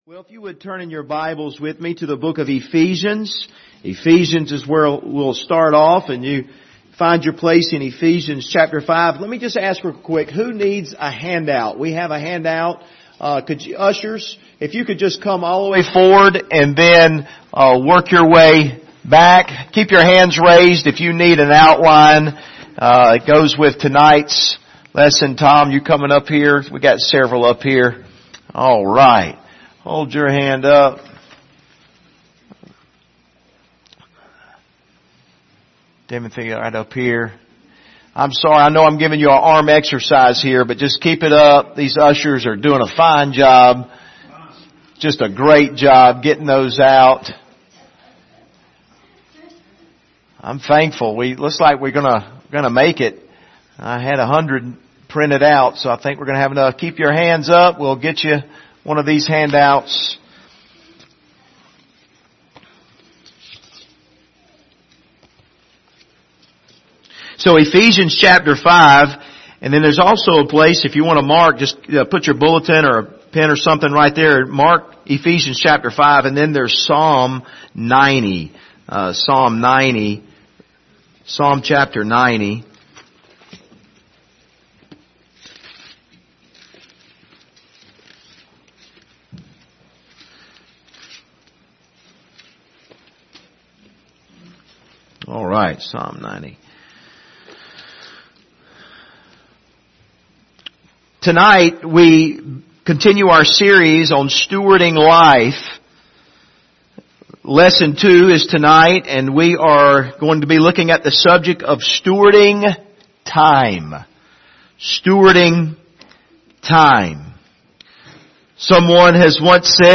Passage: Ephesians 5:14-17 Service Type: Sunday Evening